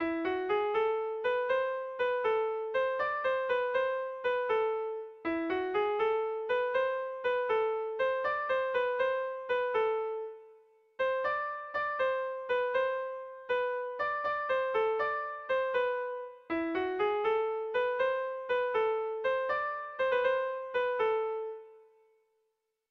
Tragikoa
Zortziko txikia (hg) / Lau puntuko txikia (ip)
AABA